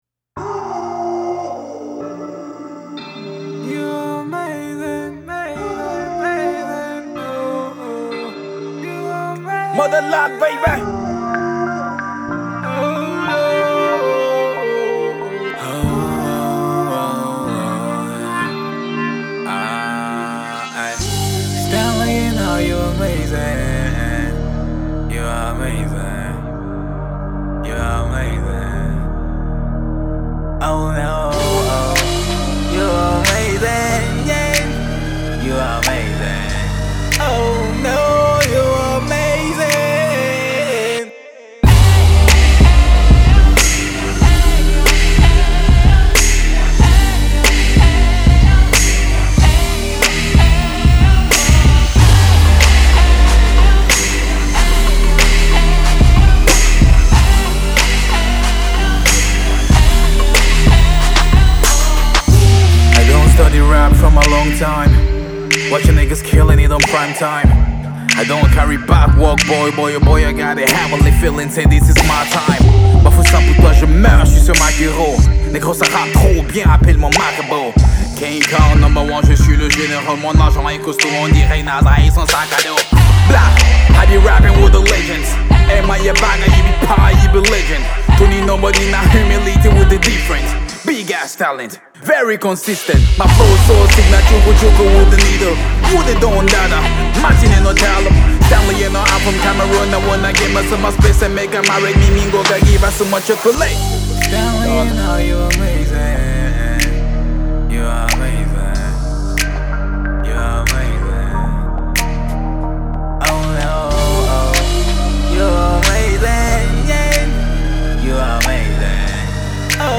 Here is something new from Cameroon Hip-Hop act